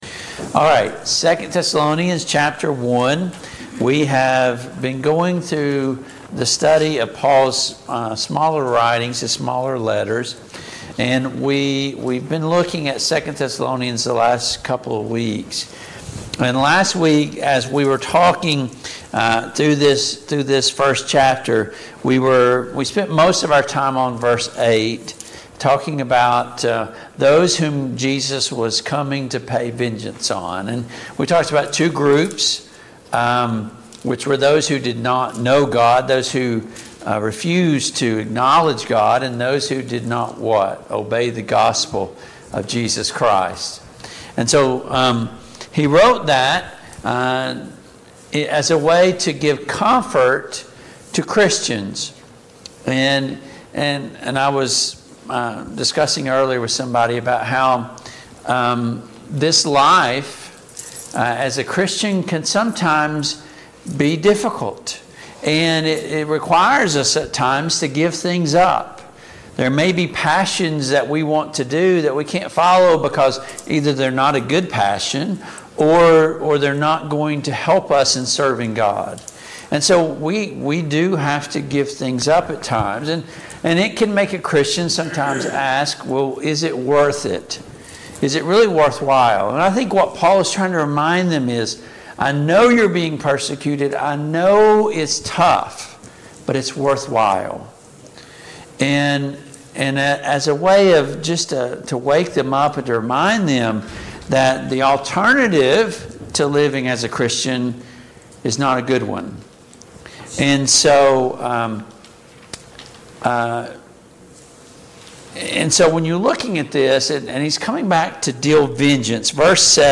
Passage: 2 Thessalonians 1:9-12, 2 Thessalonians 2:1-2 Service Type: Mid-Week Bible Study